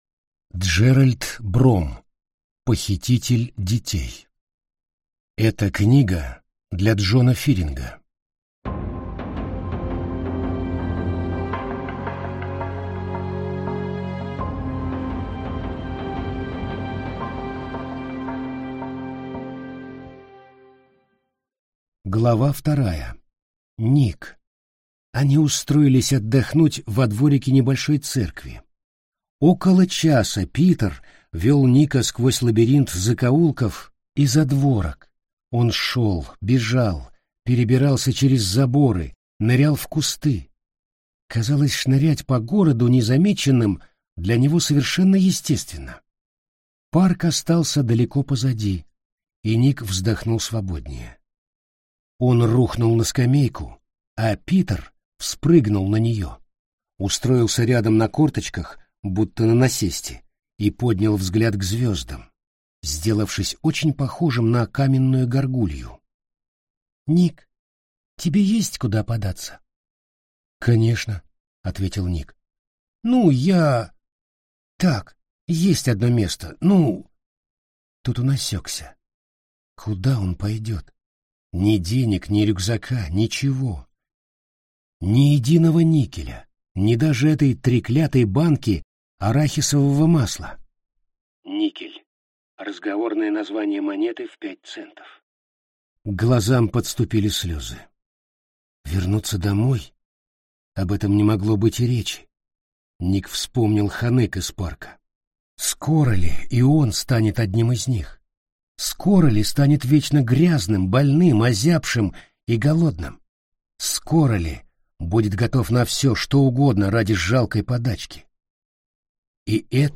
Аудиокнига Похититель детей | Библиотека аудиокниг